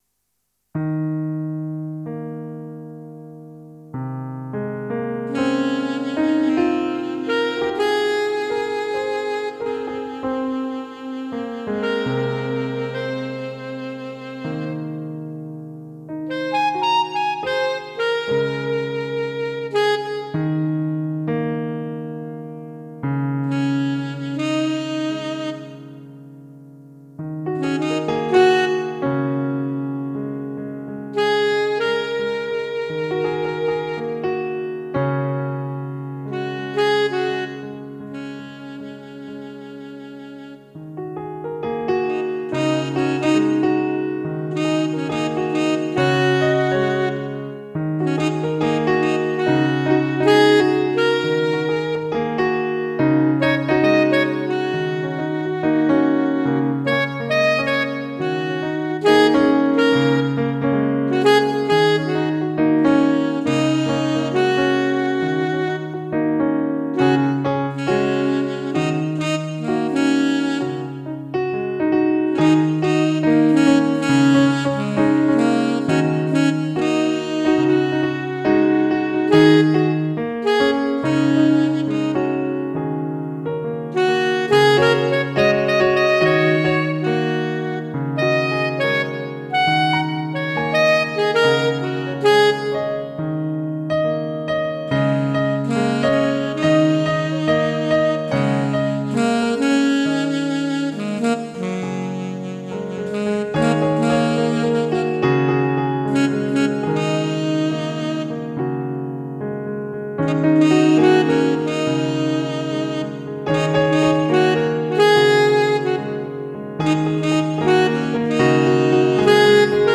Jazzig gechillte Variante
Piano und Saxophon
Tempo: bis 70 bpm / Datum: 12.12.2016